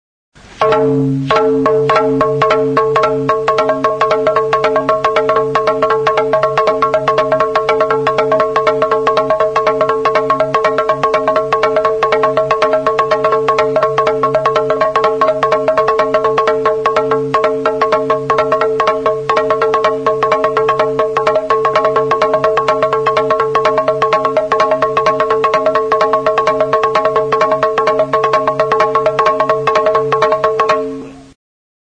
Idiófonos -> Golpeados -> Directamente
EUROPA -> EUSKAL HERRIA
Txalaparta jotzeko kono-enbor formako zurezko lau makila dira.